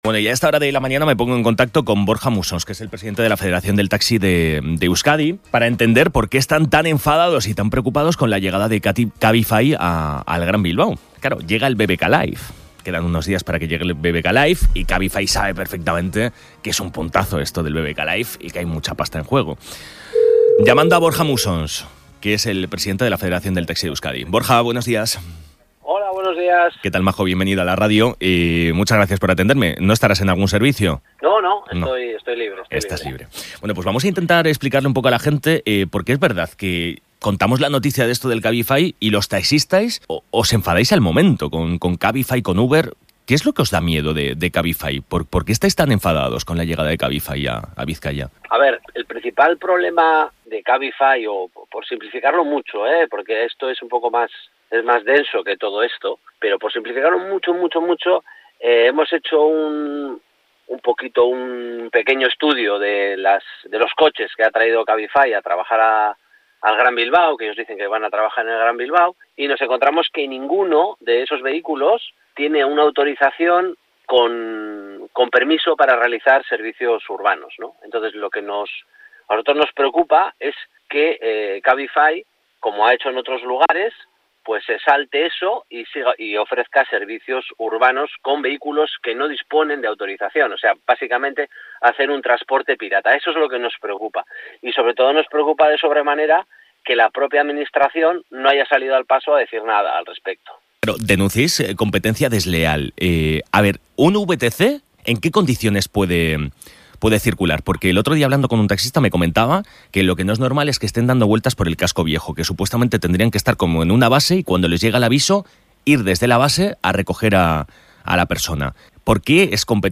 En una entrevista en El Madrugador